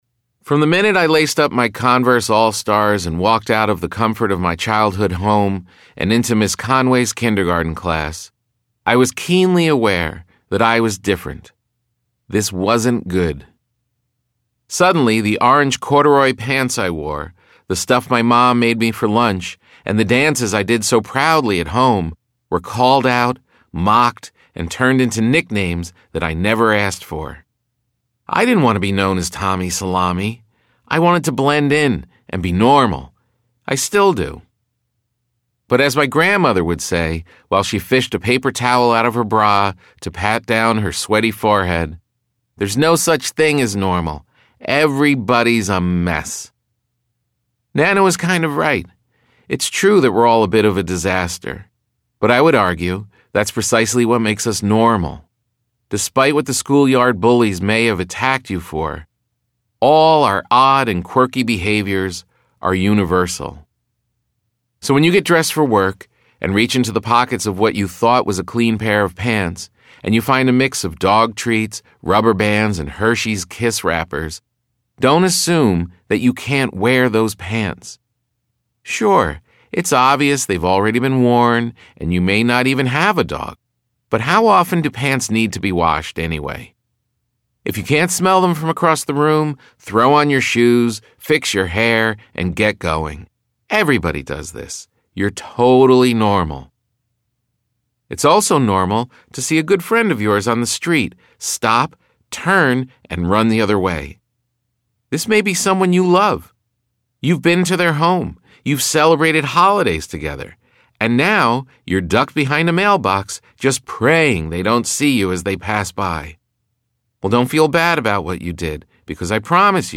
Listen to an excerpt of this laugh-out-loud audiobook narrated by Tom Papa himself.